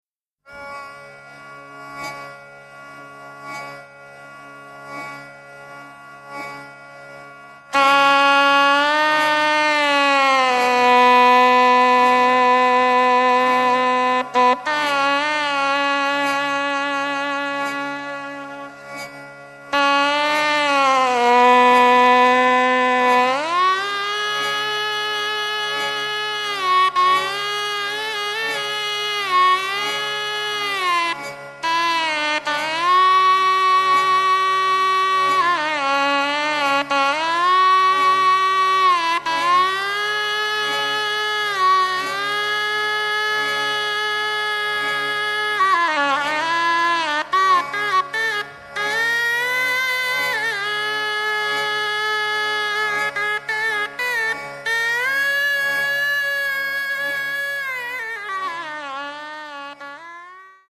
die Erfinder des "Mittelalter-Jazz"